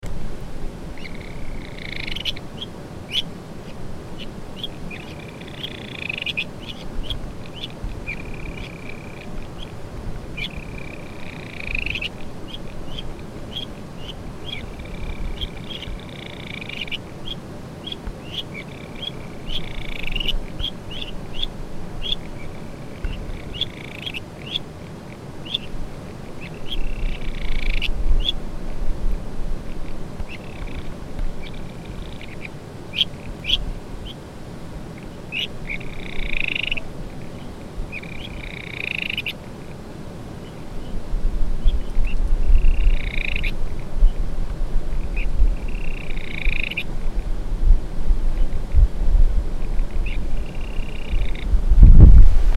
日本樹蛙 Buergeria japonica
宜蘭縣 南澳鄉 碧候溫泉
錄音環境 碧候溫泉旁
5隻以上競叫